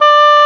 WND OBOE-E.wav